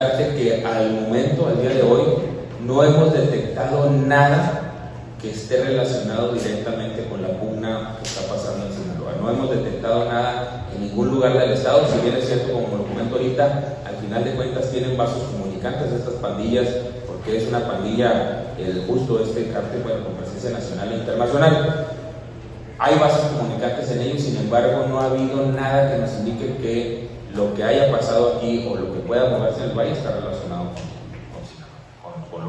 AUDIO: GILBERTO LOYA, SECRETARIO DE SEGURIDAD PÚBLICA DEL ESTADO (SSPE)